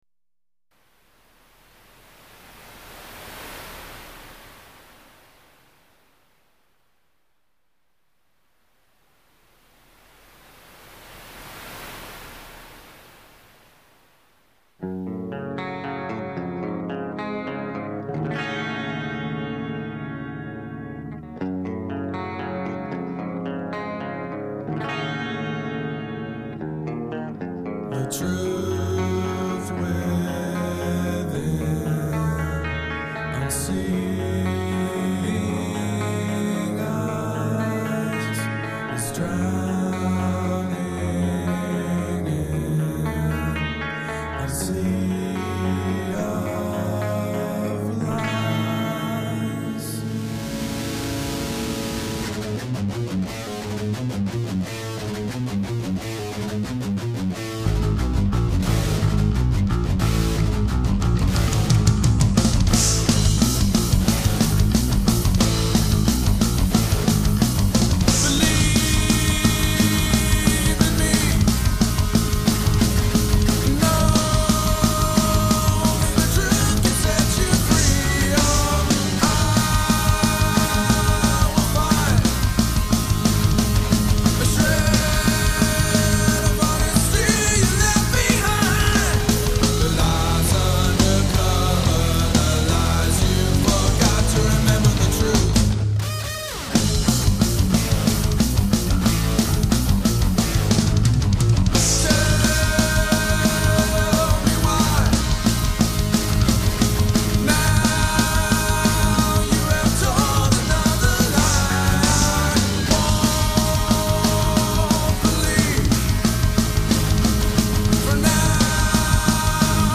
Hard Rock/Metal With Vocals (Shades Of Gray)